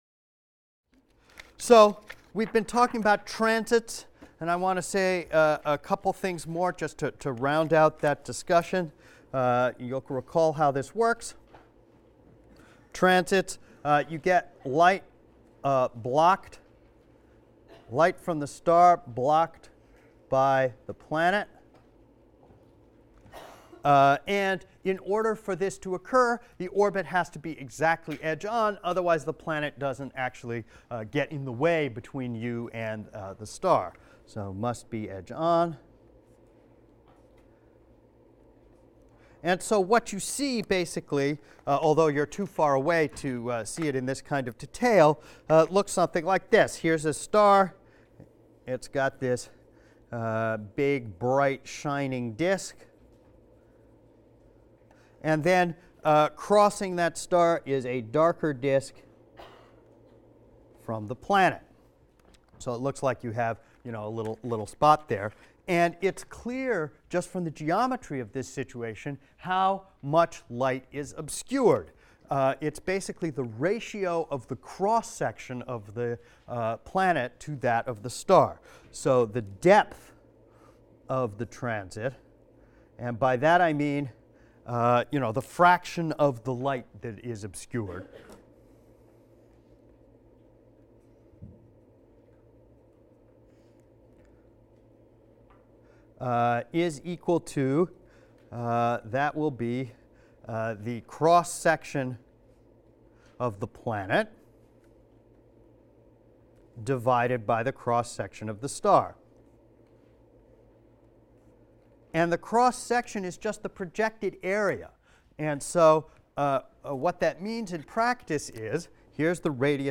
ASTR 160 - Lecture 7 - Direct Imaging of Exoplanets | Open Yale Courses